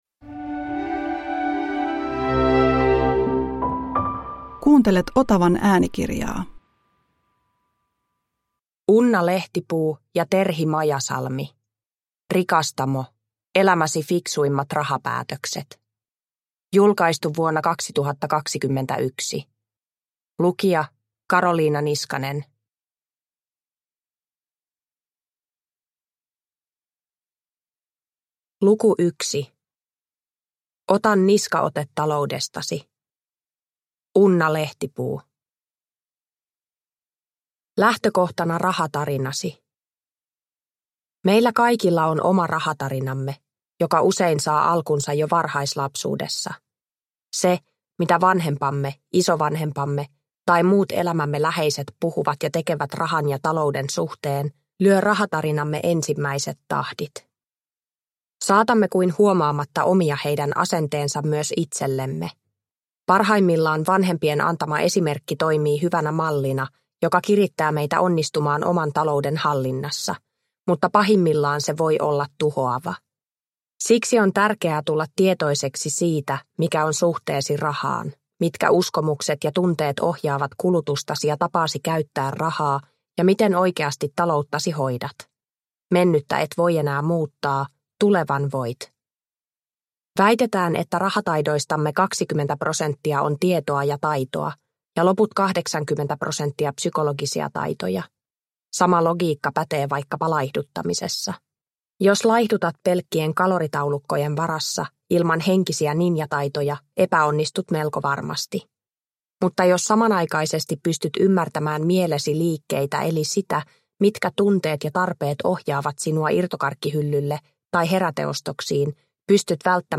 Rikastamo – Ljudbok – Laddas ner